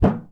metal_tin_impacts_deep_04.wav